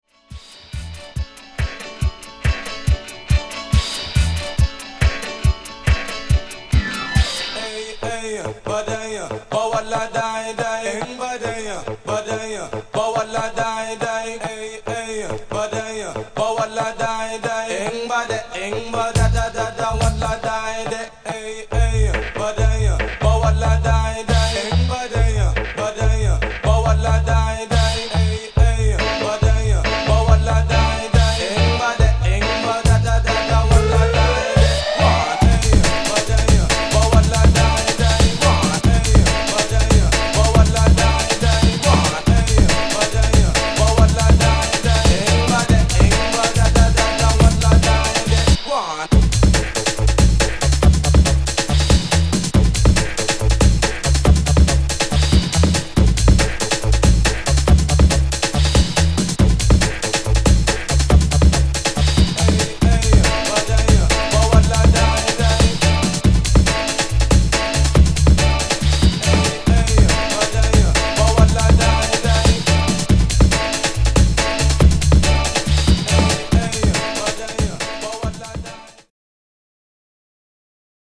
＊視聴音源は実物のレコードから録音してます。